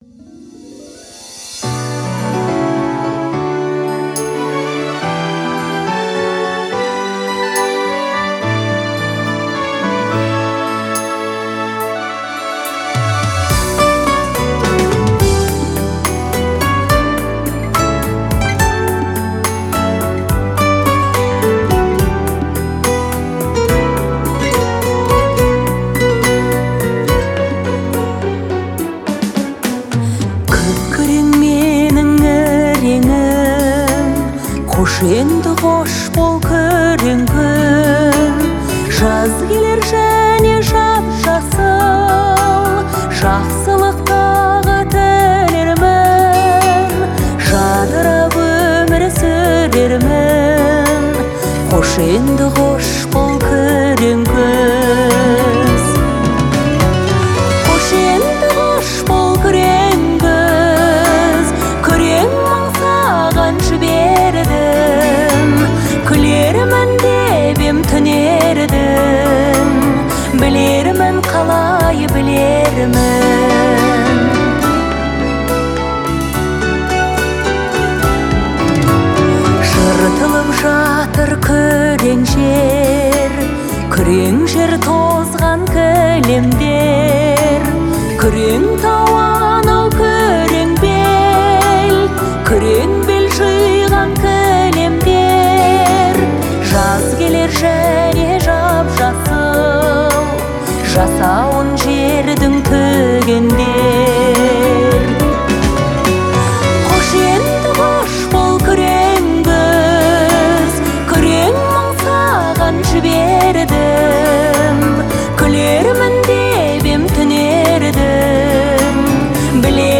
Звучание мелодии мягкое и лиричное